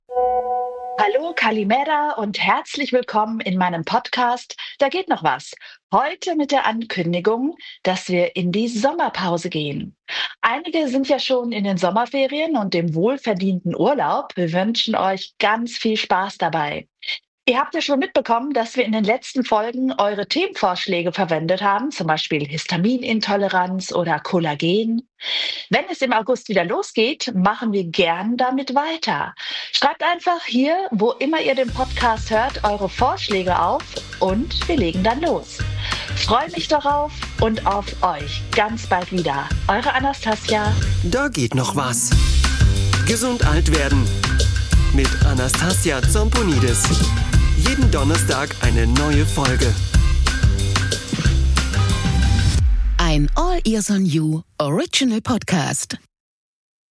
Sprachnachricht aus Griechenland